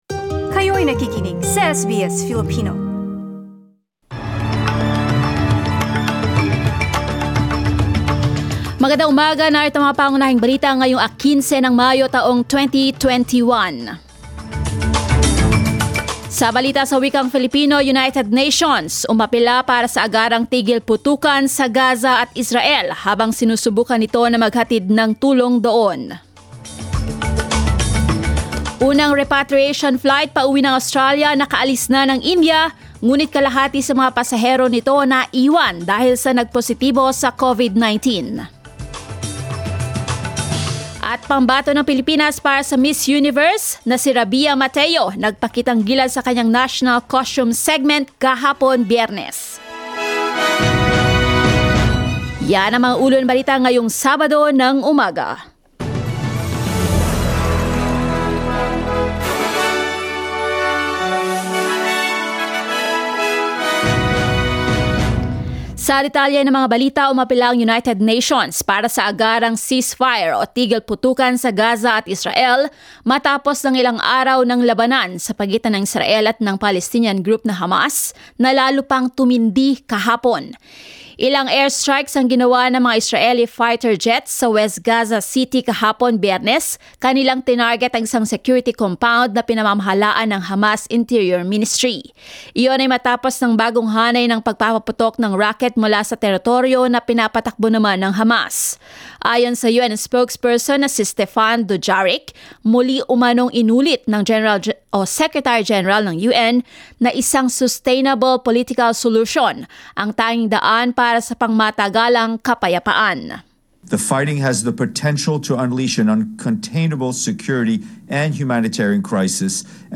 SBS News in Filipino, Saturday 15 May